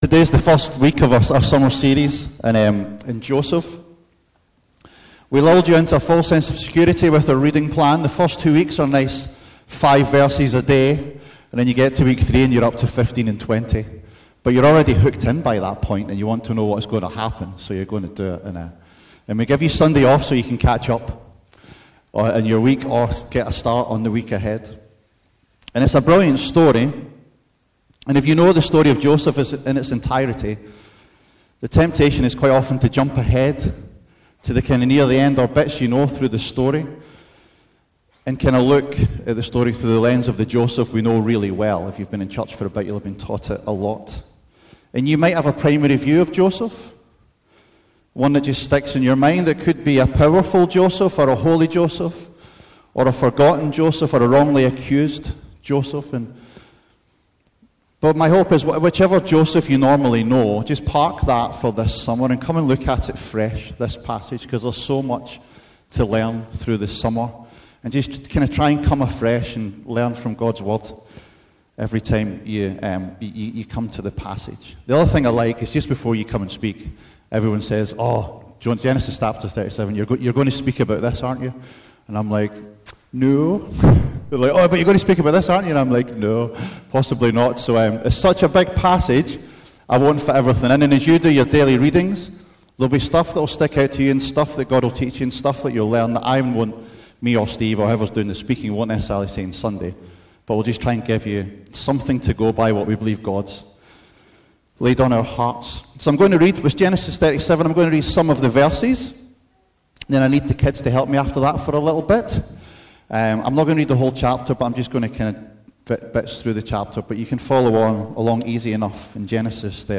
A message from the series "Joseph."